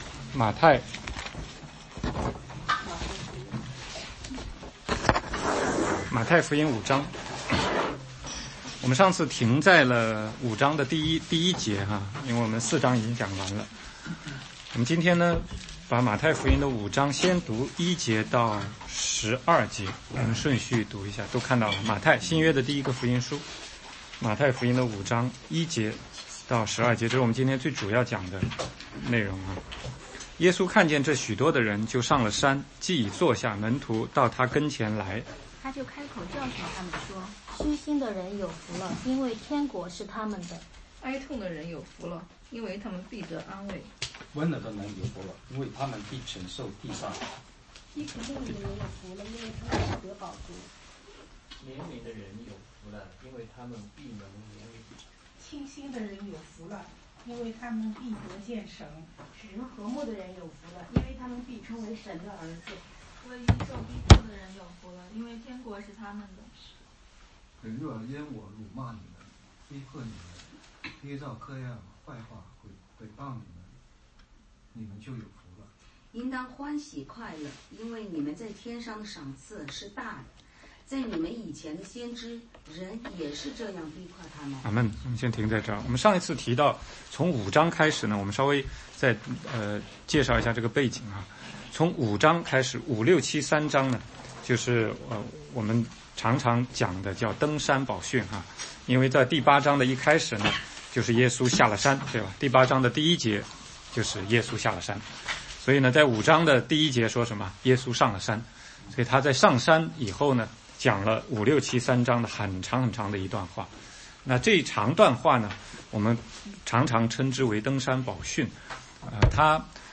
16街讲道录音 - 马太福音5章1-11